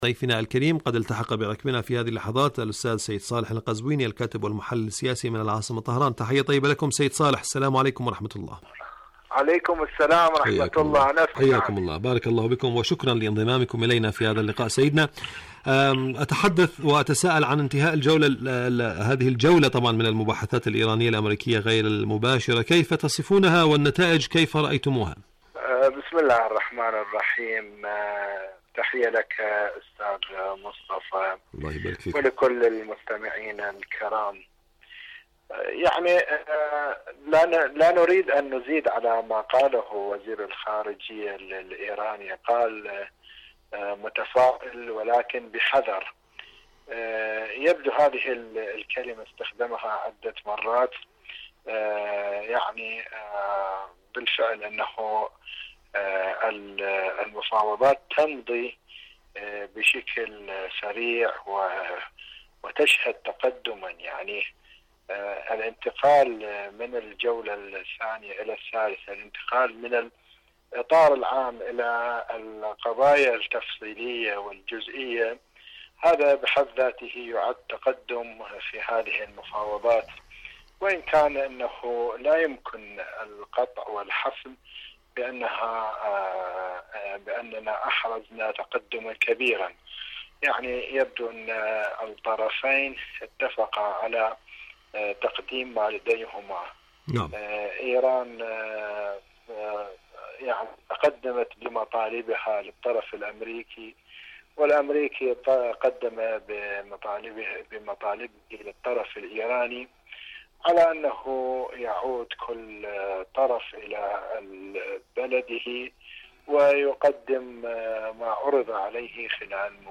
برنامج حدث وحوار مقابلات إذاعية